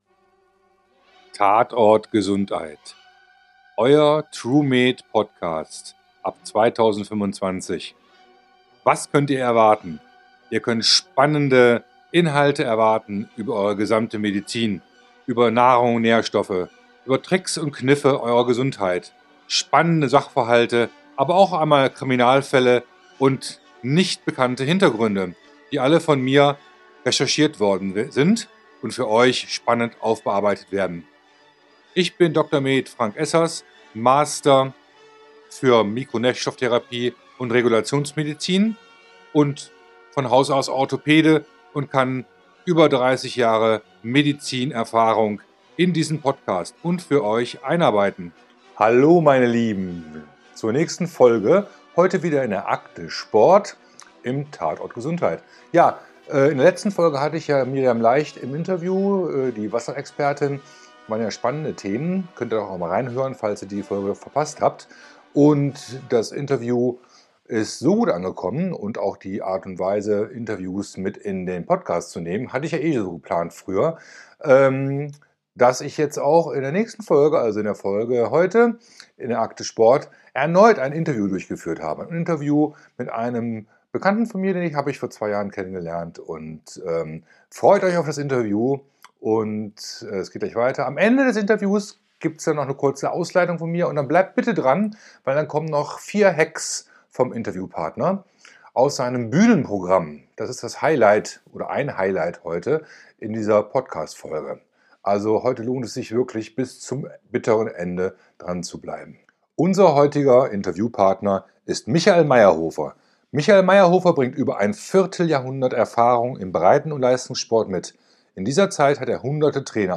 Interview: Sport-MOTIVATION, Bewegungs-MOTIVATION und Spiraldynamik nicht nur für Führungskräfte